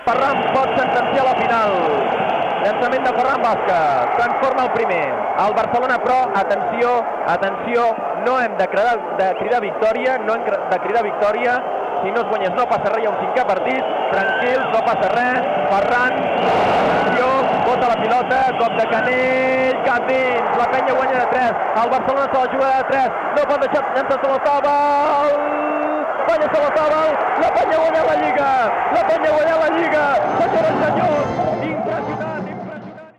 Transmissió dels últims segons del partit del "play-off" pel títol de la Lliga ACB, de bàsquet masculí, entre el Montigalà Joventut i el F.C. Barcelona.
Esportiu